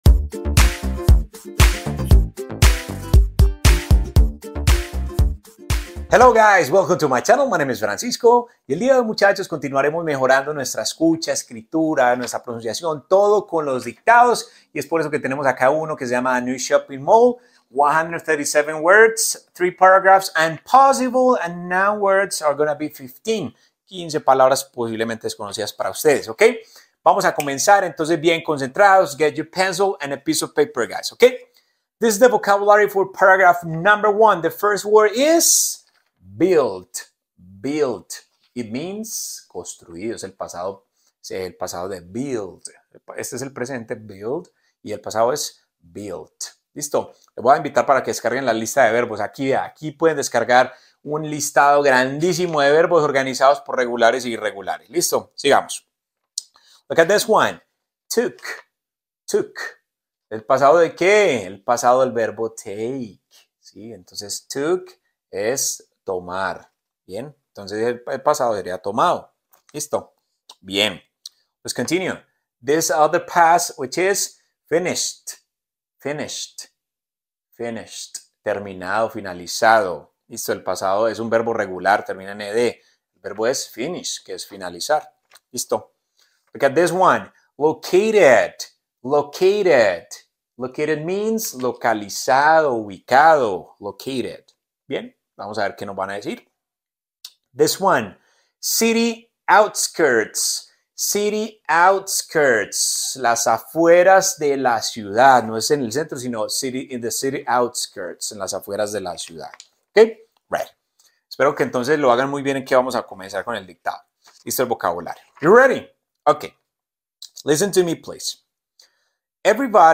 Nuevo shopping mall en inglés – Dictado práctico nivel A2 que mejora tu listening al instante